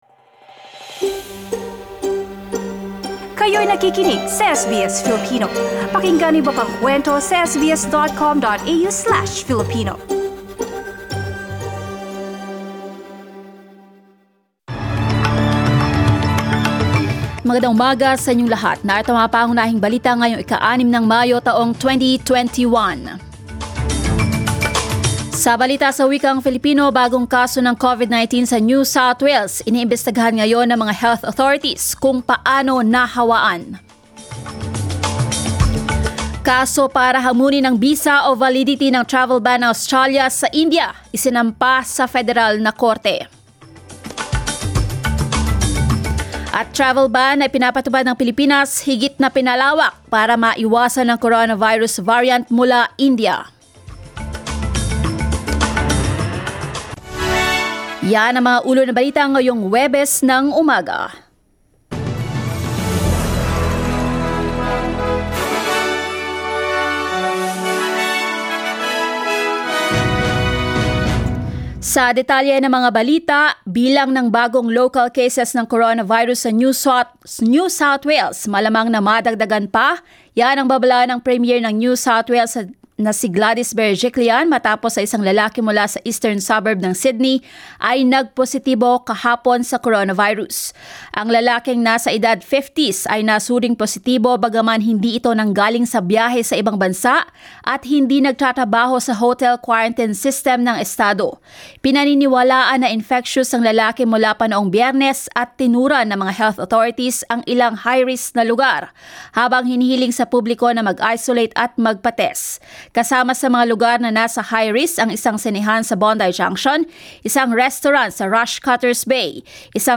SBS News in Filipino, Thursday 6 May